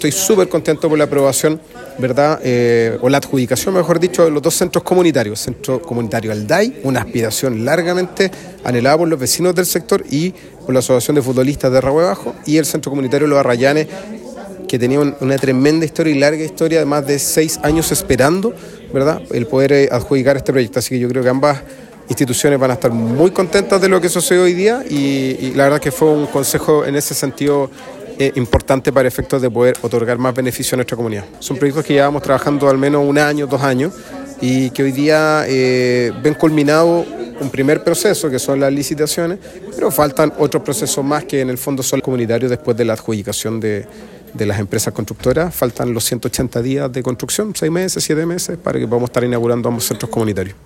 Por su parte, el concejal Miguel Arredondo celebró la adjudicación de estos proyectos, recordando que ambas comunidades habían esperado durante años por la construcción de estos espacios que finalmente se concretará en beneficio de los vecinos y sus actividades impulsando el desarrollo comunitario.